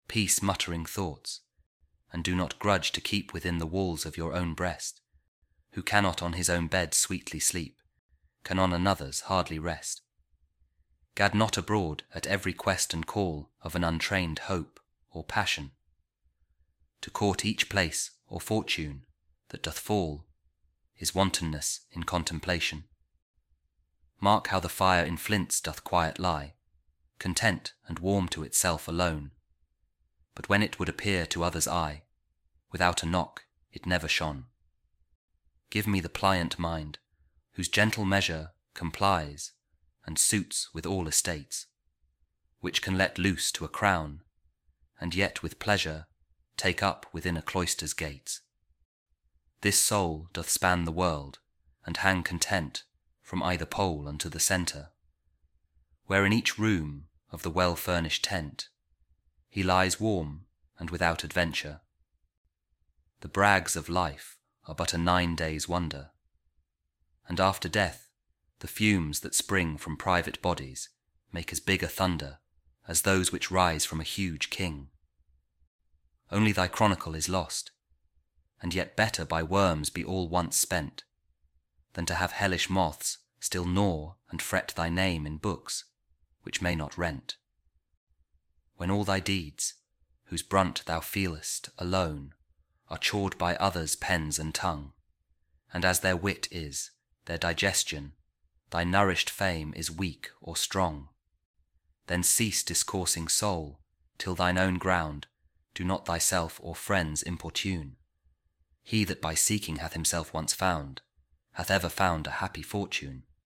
George Herbert | The Temple | Church | Content | Audio Poem